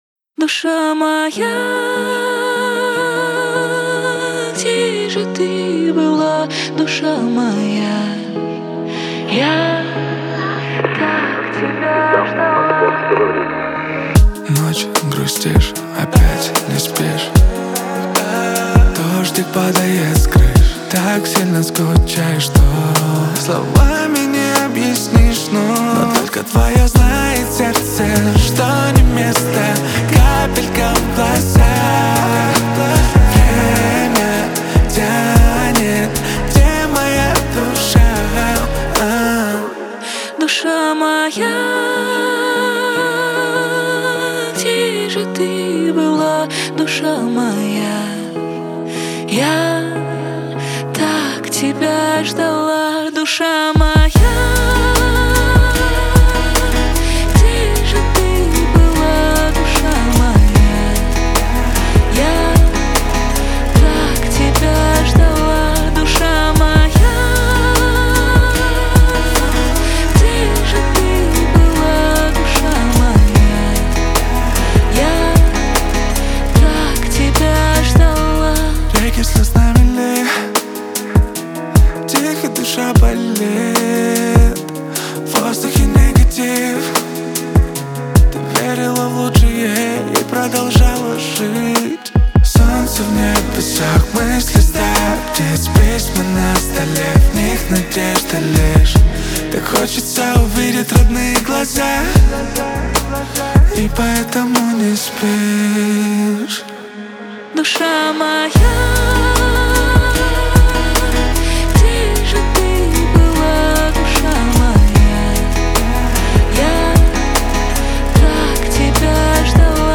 Качество: 320 kbps, stereo
Русские поп песни